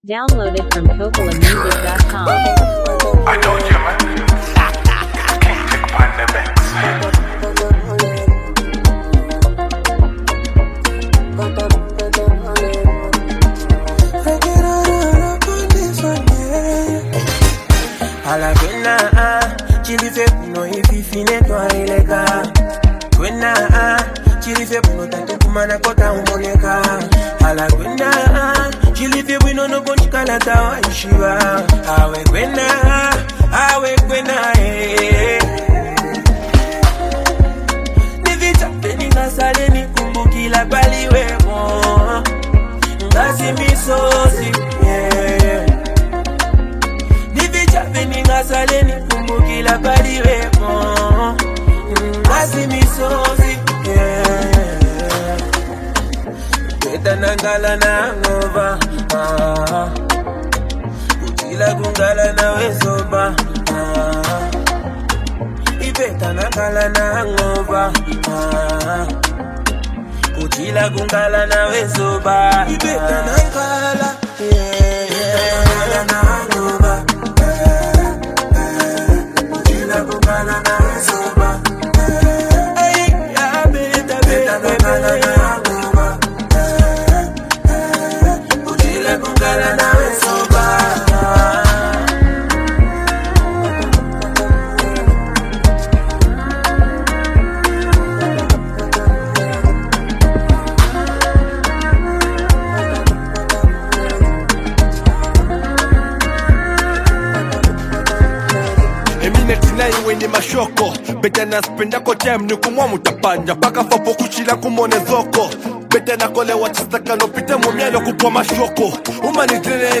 contemporary Zambian music